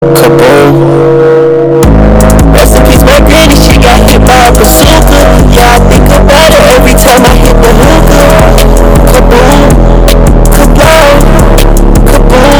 Bazooka Bass Boosted Sound Button - Free Download & Play